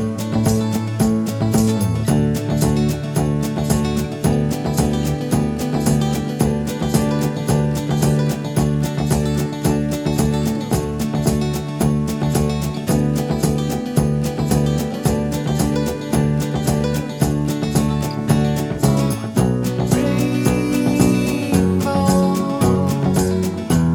Minus All Guitars For Guitarists 4:05 Buy £1.50